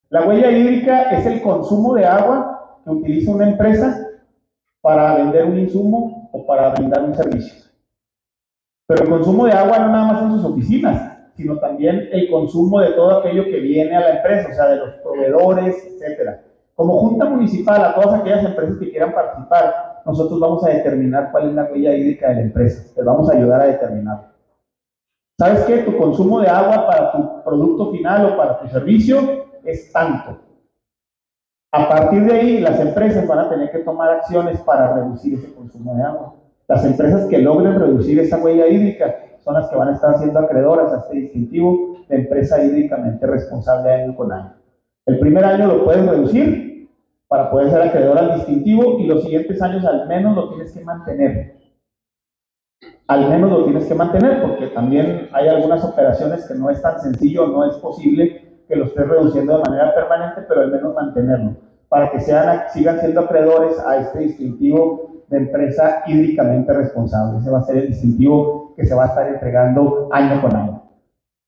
AUDIO: ALAN FALOMIR, DIRECTOR EJECUTIVO DE LA JUNTA MUNICIPAL DE AGUA Y SANEAMIENTO (JMAS) CHIHUAHUA